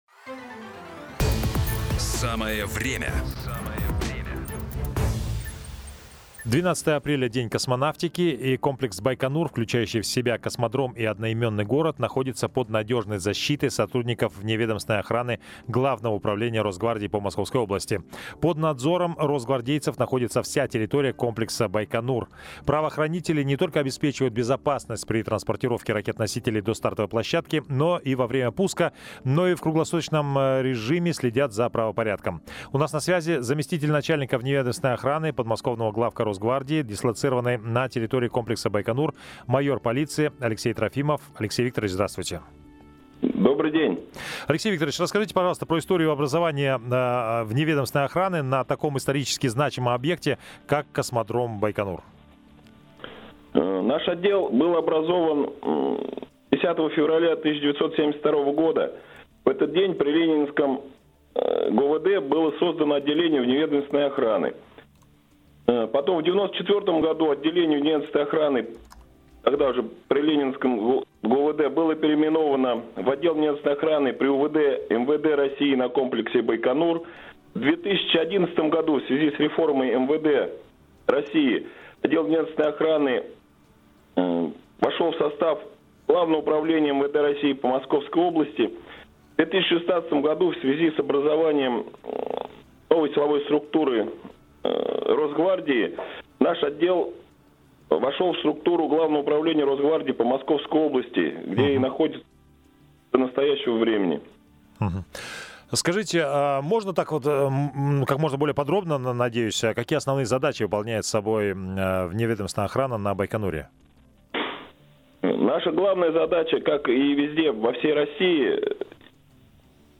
В эфире подмосковного «Радио 1» офицер Росгвардии рассказал о том, как обеспечивается безопасность на космодроме «Байконур» – Новости Росгвардии